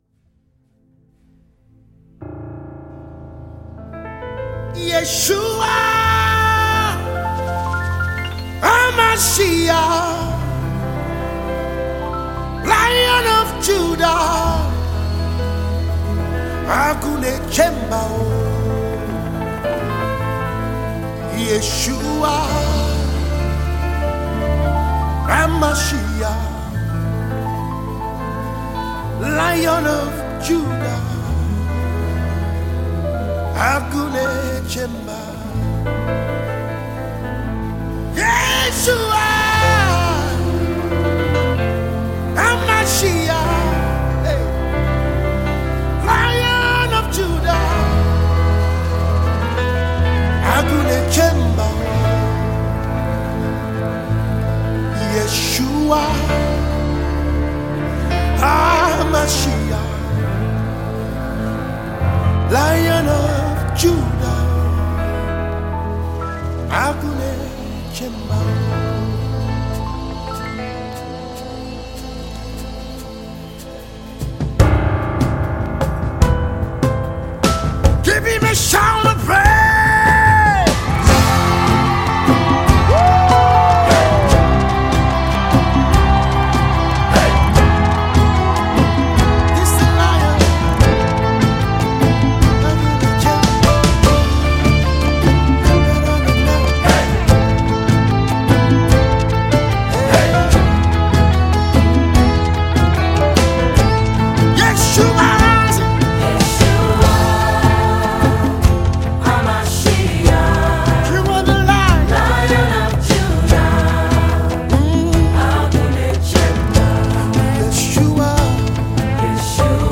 Gospel Minister